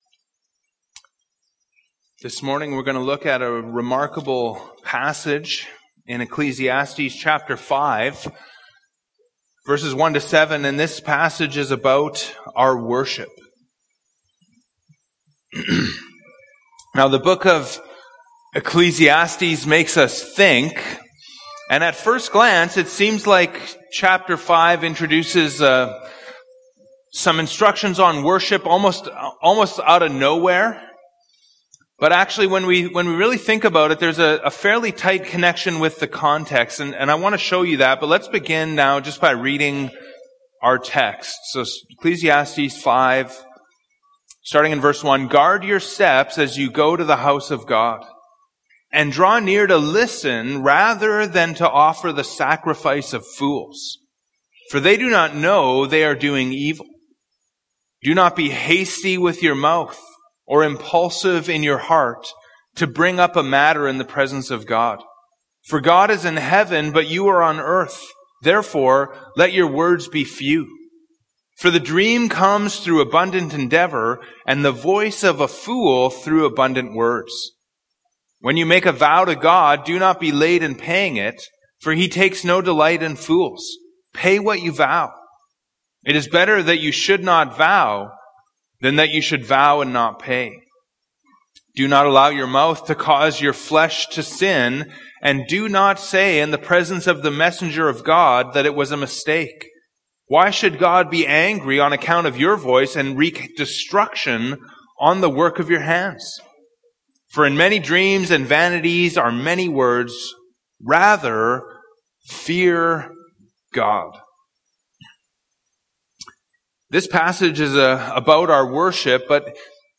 Category: Sermon